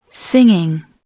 singing0.mp3